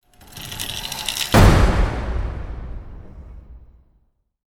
DoorWeightedOpen.wav